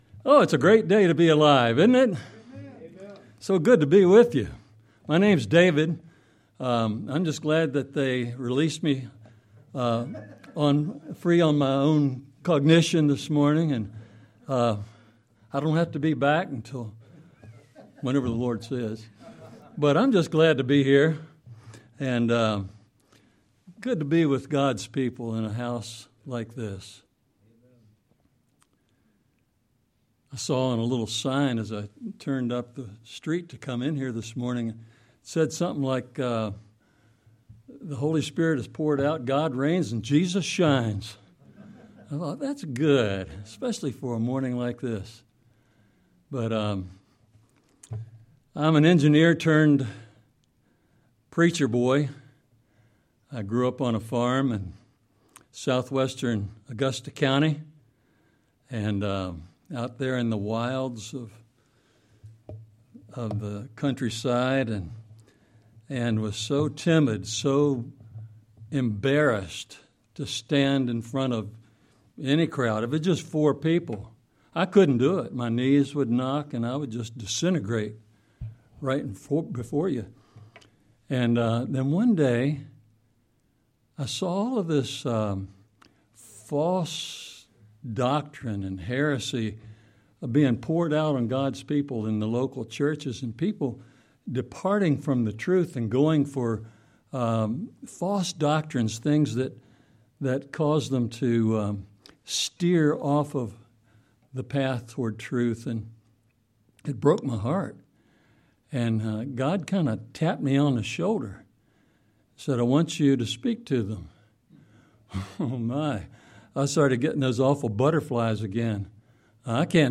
Sermon-3-29-26.mp3